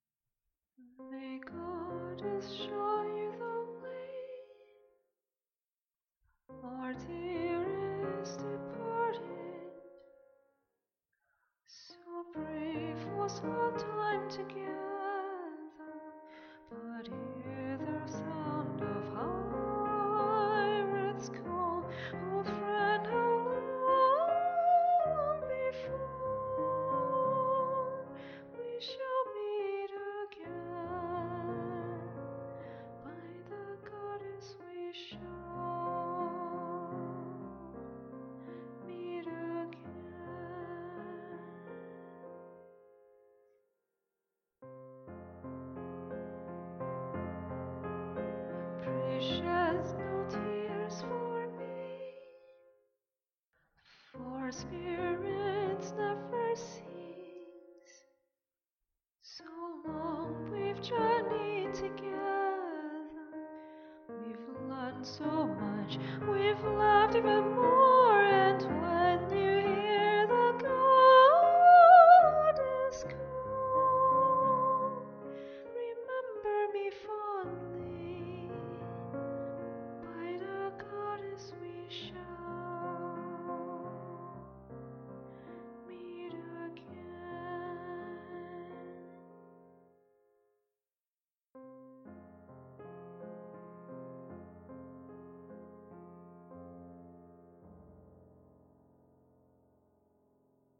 The previous song, with vocals! The first part is the voice of the mourner, while the second part is the voice of the deceased. I also wanted to make a choral rendition of the voice of the deceased by singing this song like 30 times by myself.